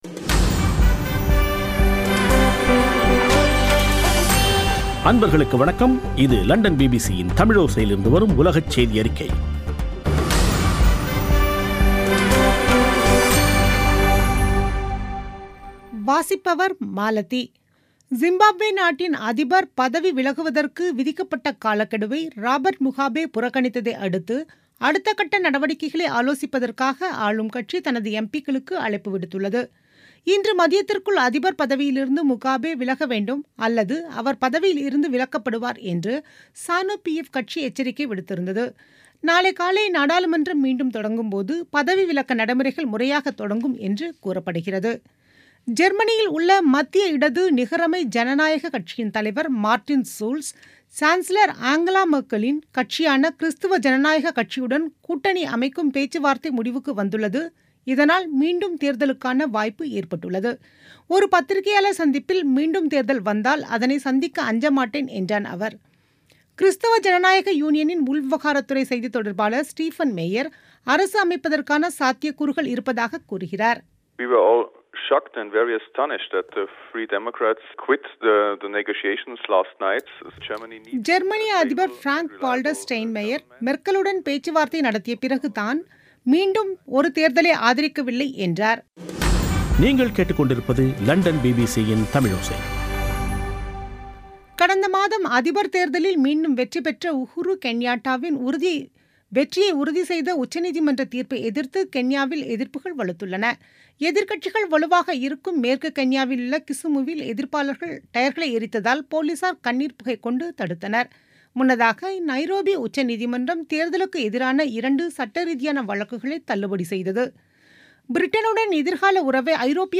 பிபிசி தமிழோசை செய்தியறிக்கை (20/11/2017)